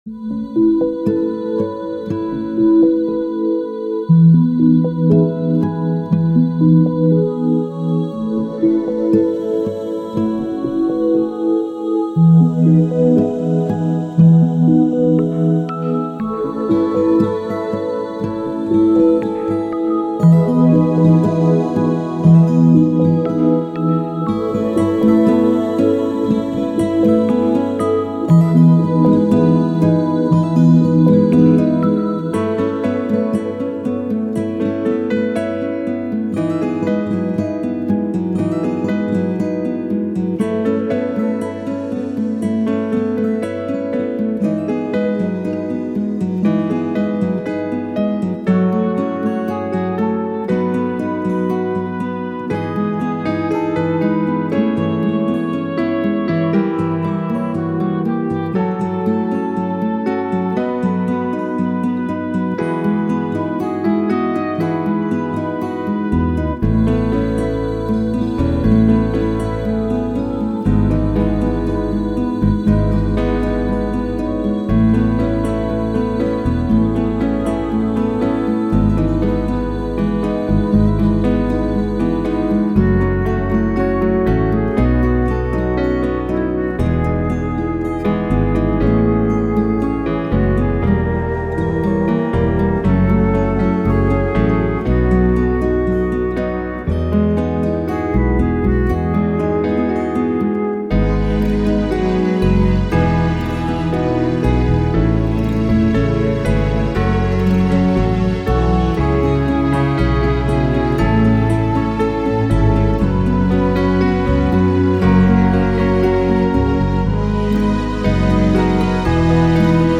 This medley of lush song arrangements convey peacefulness.